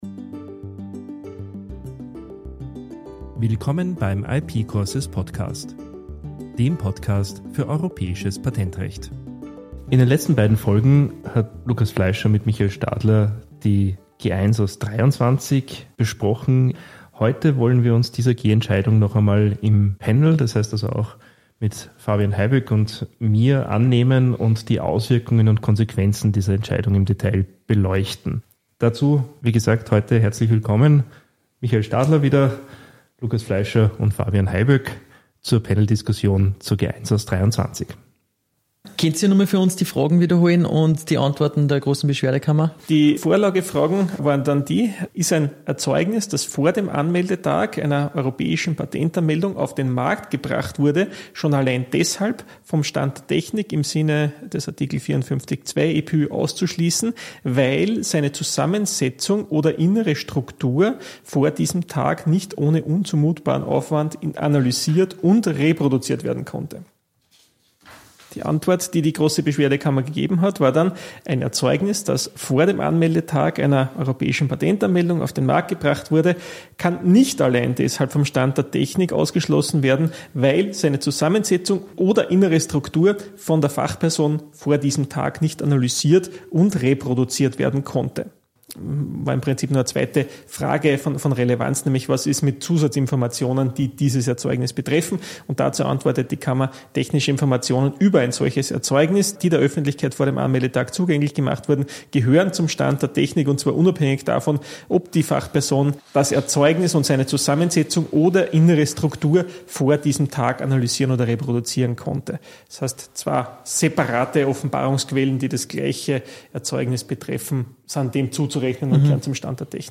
im gewohnten Panel